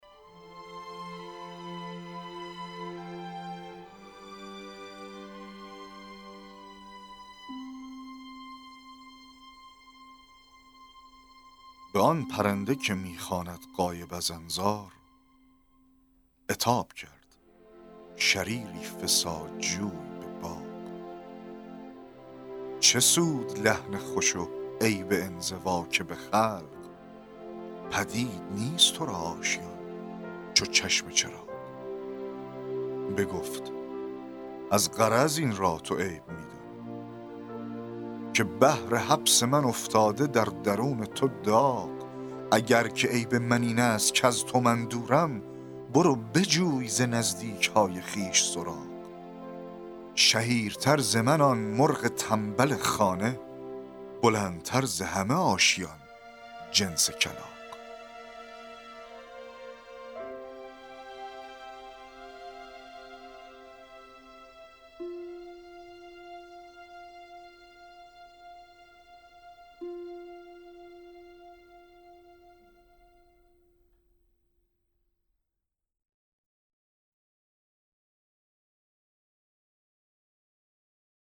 دکلمه و تحلیل شعر, شعرهای نیما یوشیج